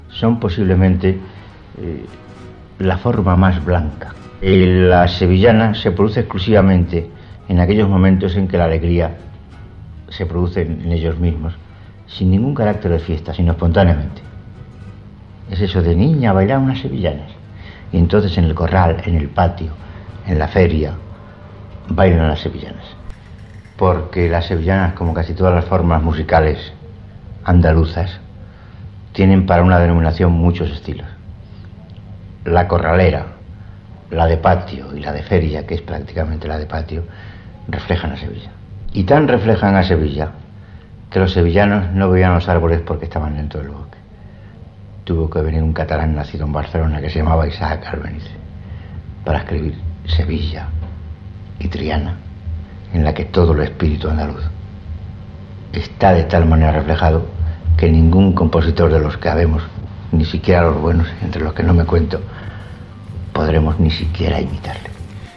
Fragment extret del programa "Audios para recordar" de Radio 5 emès el 16 d'abril del 2018.